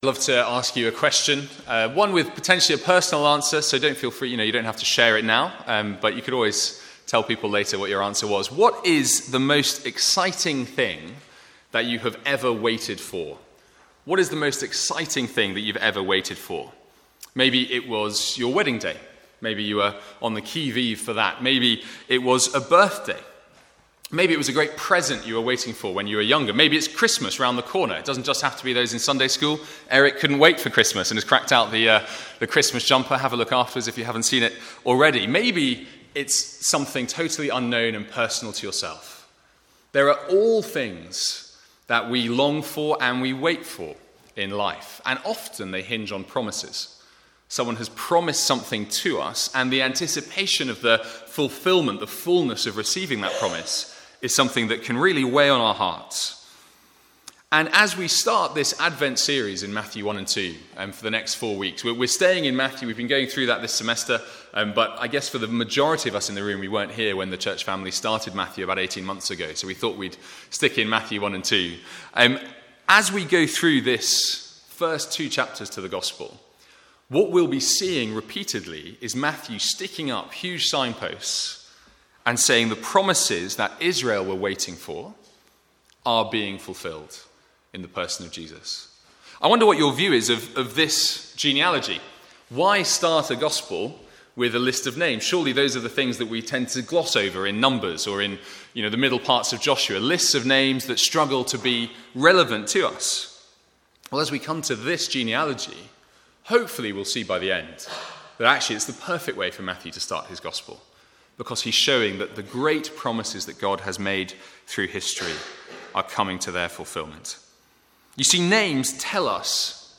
From the Sunday morning advent series (2014).